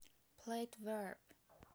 plate verb
Significado : Um tipo de reverb fabricado onde o som é reverberado em placas de metal bi ou tri dimensionais, aumentando a suavidade das frequências musicais graves e o brilho das frequências agudas.